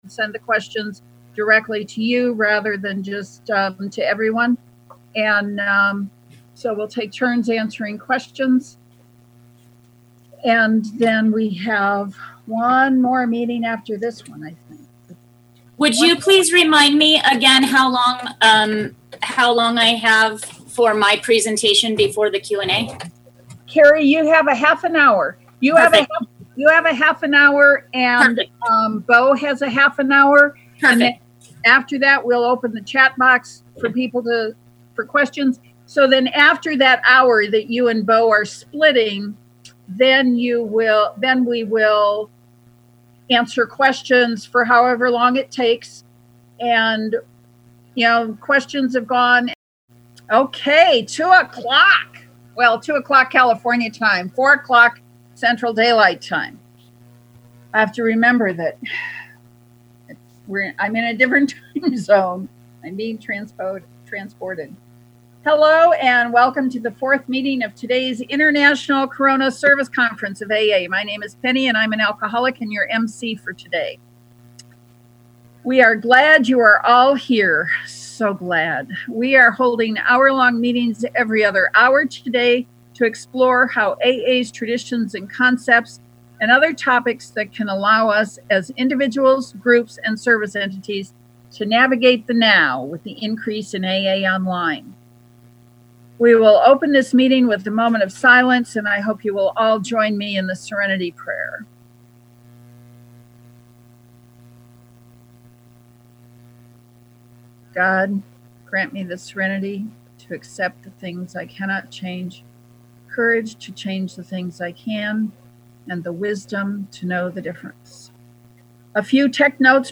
Online Anonymity International Corona Service Conference of AA Worldwide 4-4-2020